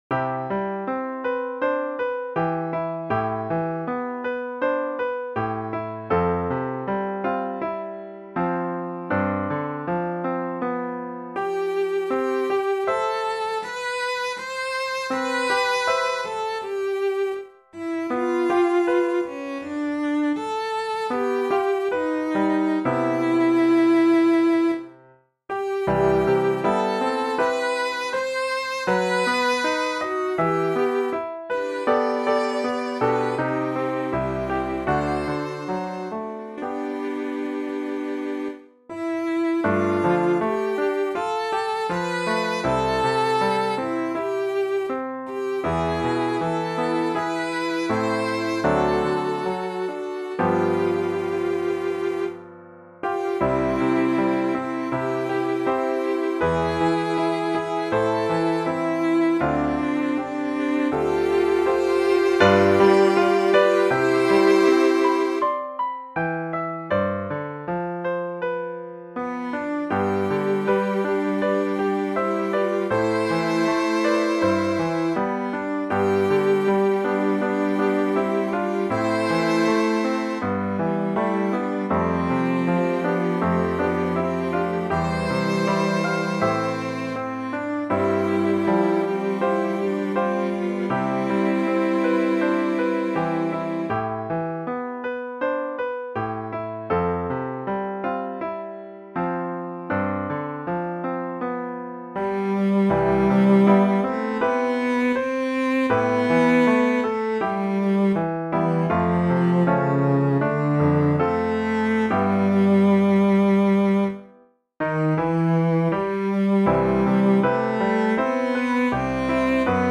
SATB arrangement of 2 LDS hymns
Here is a computer generated mp3 recording.